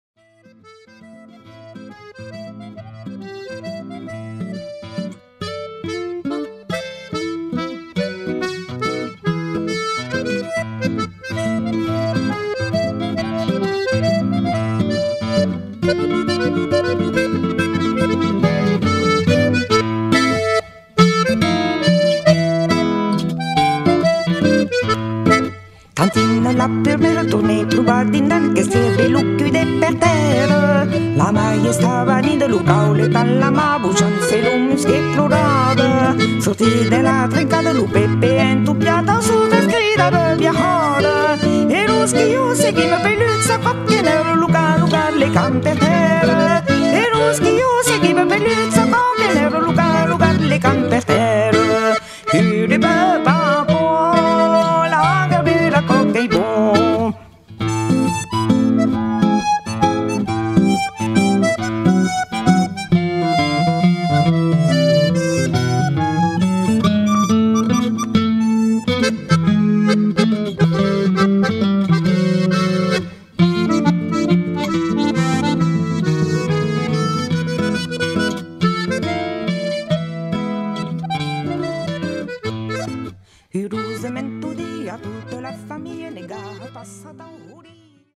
Masurca
acordeon, cornamusas, flautas, percussions, cant
guitarras, percussions, cant